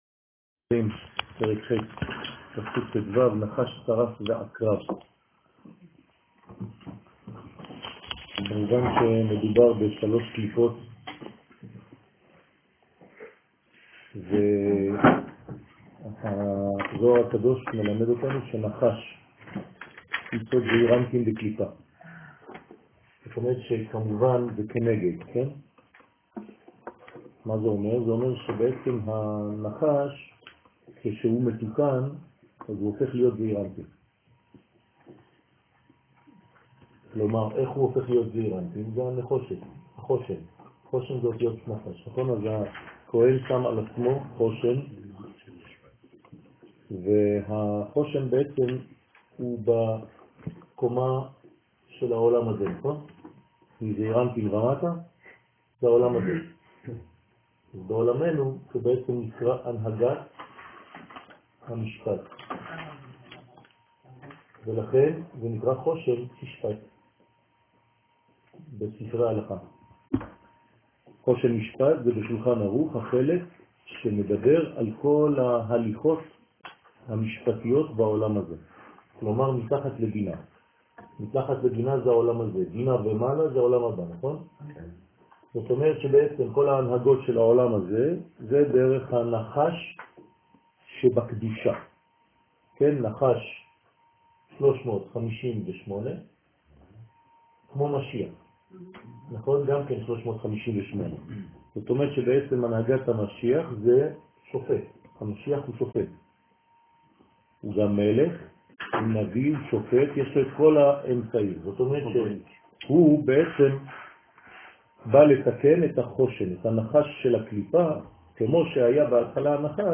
שיעור לילה : תיקוני זוהר ב פרק ח